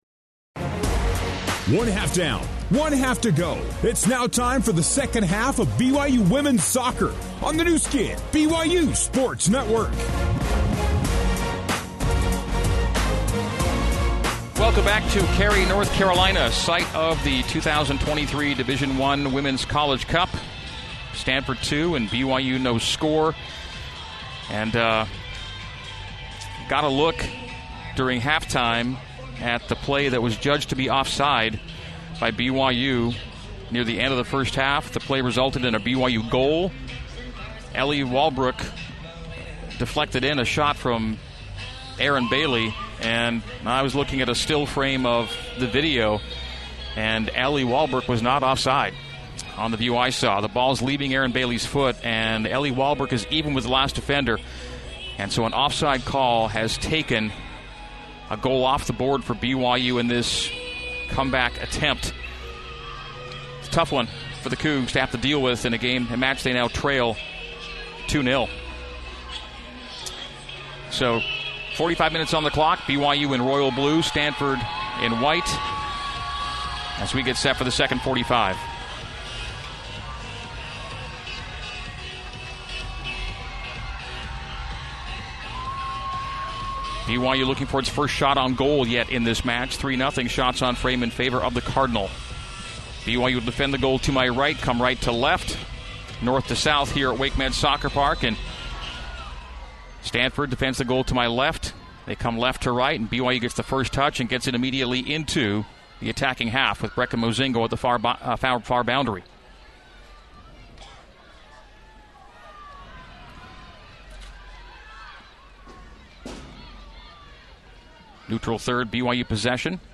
Enjoy archived radio play-by-play broadcasts of BYU women's soccer games!